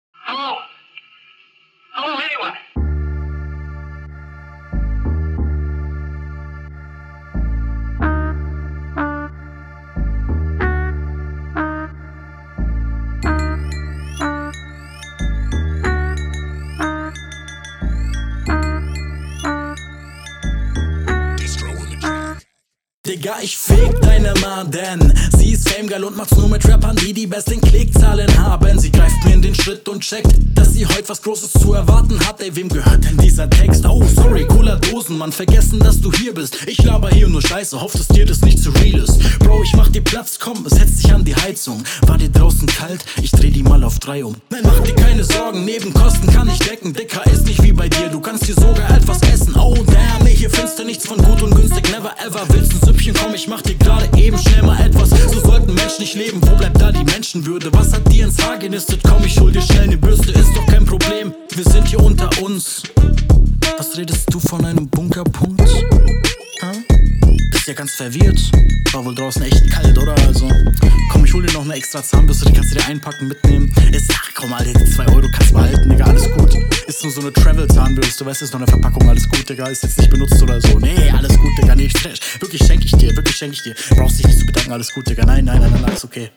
Flow bisschen besser, aber wieder nur hart whacke lines, besonders die Mutter line.